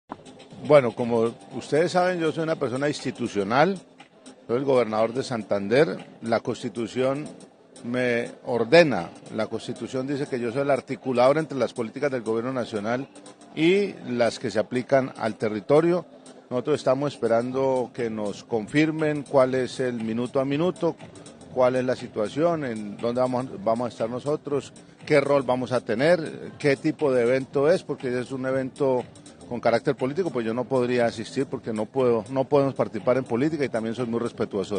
Juvenal Díaz Mateus, gobernador de Santander